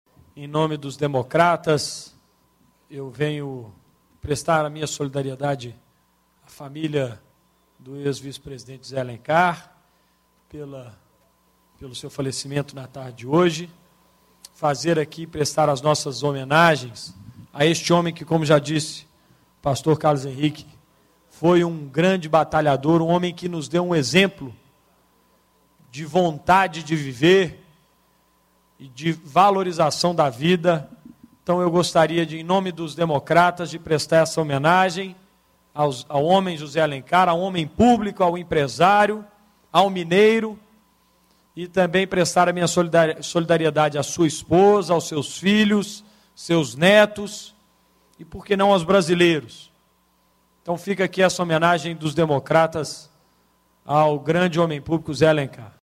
Reunião de Plenário - Pronunciamento sobre o ex-vice-presidente da república, José Alencar